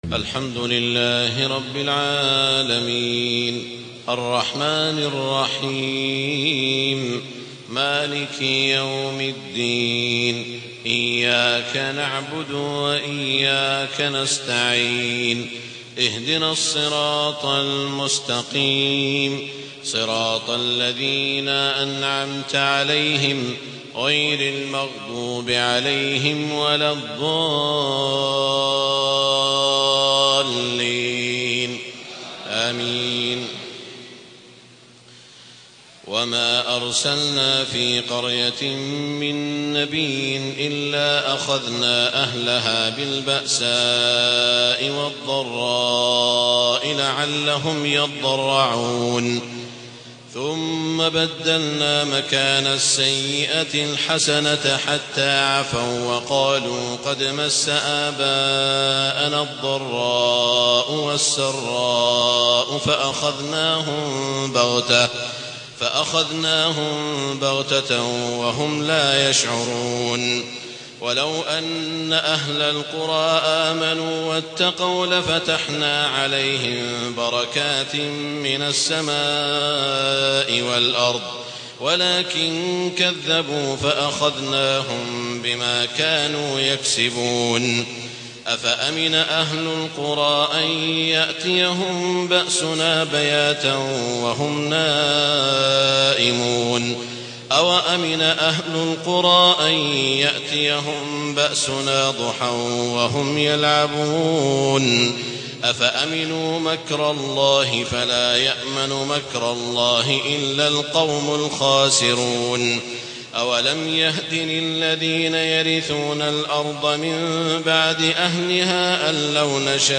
تهجد ليلة 29 رمضان 1432هـ من سورة الأعراف (94-188) Tahajjud 29 st night Ramadan 1432H from Surah Al-A’raf > تراويح الحرم المكي عام 1432 🕋 > التراويح - تلاوات الحرمين